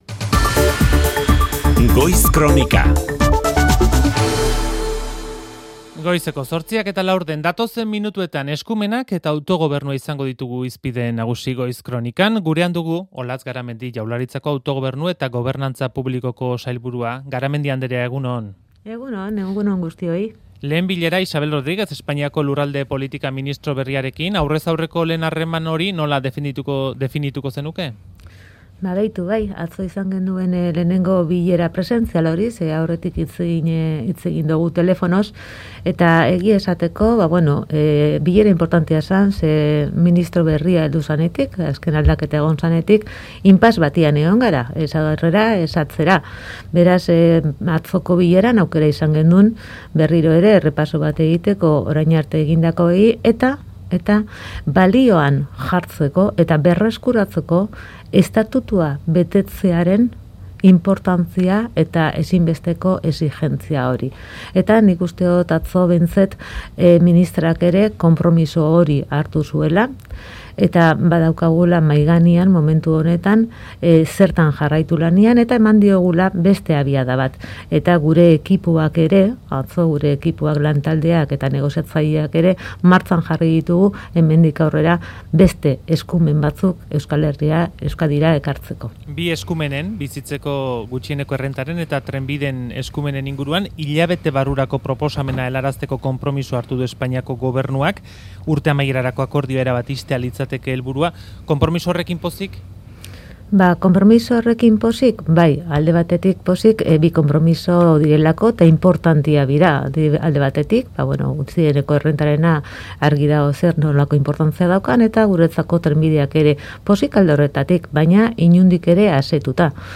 Euskadi Irratiko Goiz Kronikan izan da.
Olatz Garamendi Jaurlaritzako Autogobernu eta Gobernantza Publikoko sailburua izan dugu Euskadi Irratiko Goiz Kronikan. Bizitzeko gutxieneko errentaren eta trenbideen eskumenak hilabete barru izan daitezkeela adierazi du eta ez du baztertu gehiago ere izan daitezkeenik.